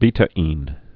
(bētə-ēn, -ĭn)